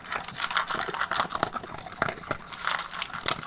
peteat.ogg